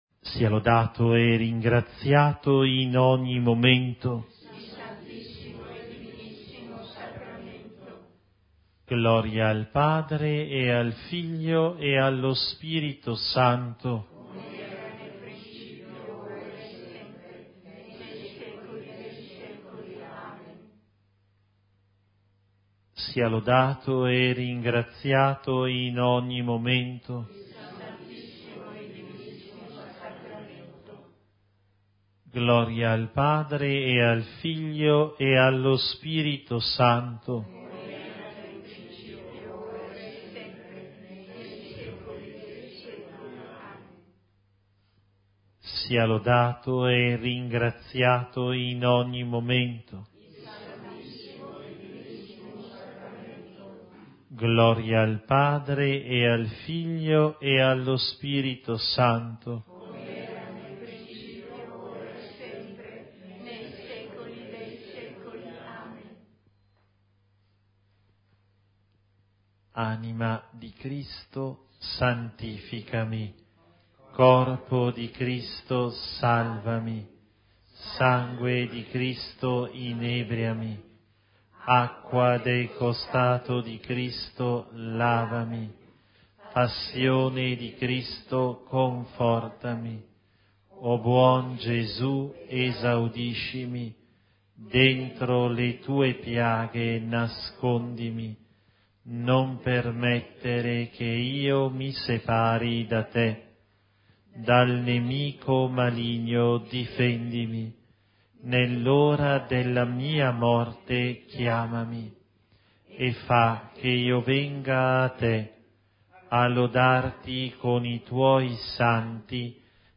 Adorazione a Gesù eucaristico